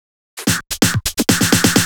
Fill 128 BPM (37).wav